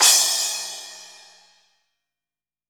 Index of /90_sSampleCDs/AKAI S6000 CD-ROM - Volume 3/Crash_Cymbal2/CHINA&SPLASH
ZIL20SPLASH.WAV